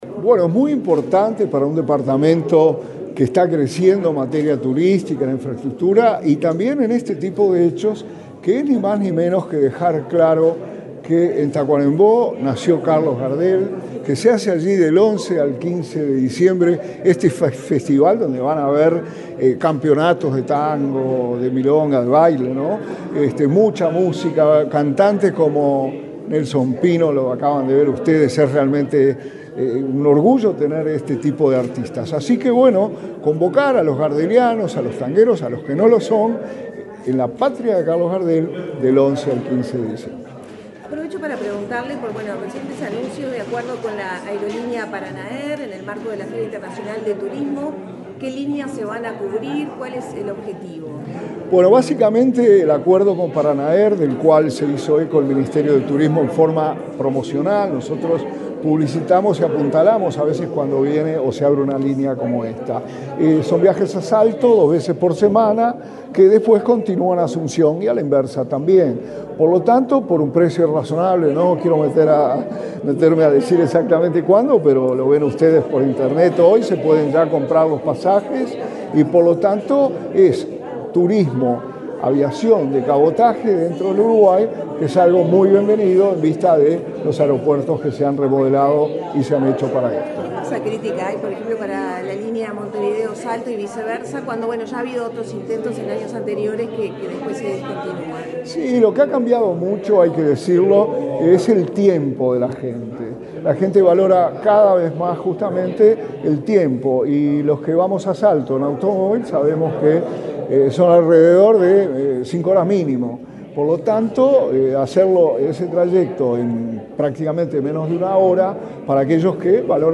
Declaraciones del subsecretario de Turismo, Remo Monzeglio
El subsecretario de Turismo, Remo Monzeglio, dialogó con la prensa, luego de participar del lanzamiento del Festival Internacional de Tango Carlos